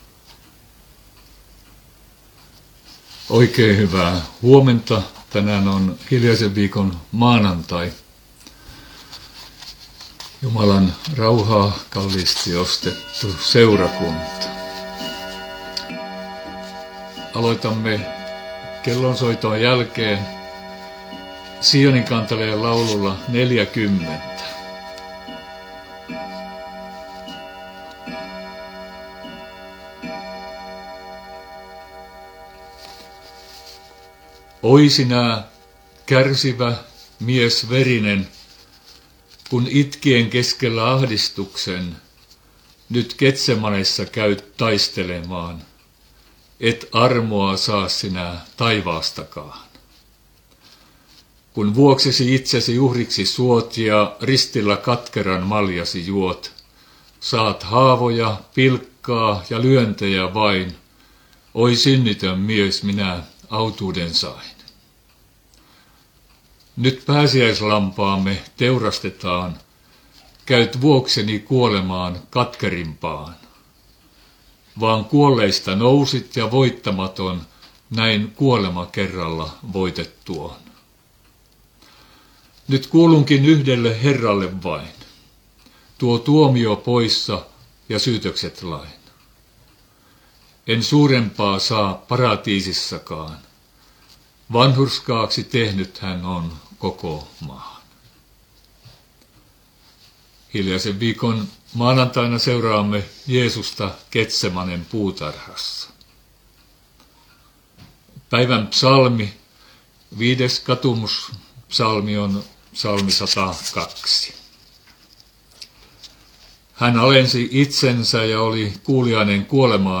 aamuhartaus hiljaisen viikon maanantaina Pohjana mm. psalmi 102